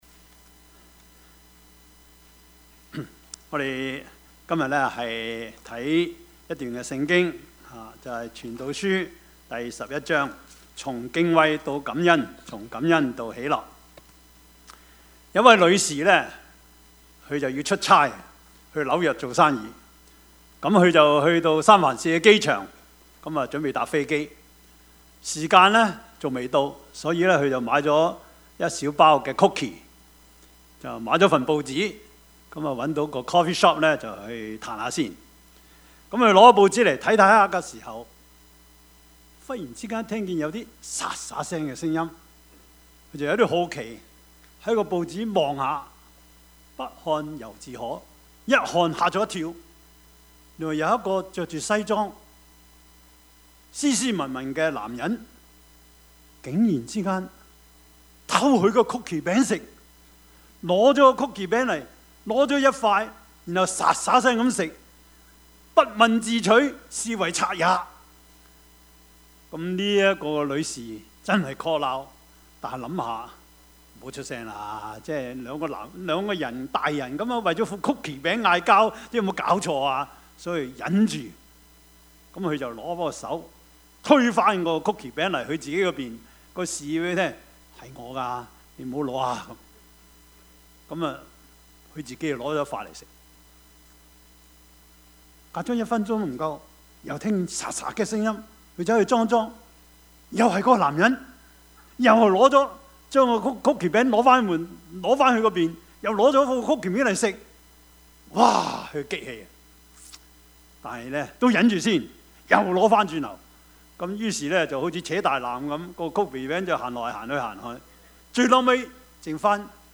Service Type: 主日崇拜
Topics: 主日證道 « 更重與更多 聽誰的?